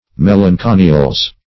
Melanconiales - definition of Melanconiales - synonyms, pronunciation, spelling from Free Dictionary
Melanconiales \Mel`an*co`ni*a"les\, n. pl. [NL., fr.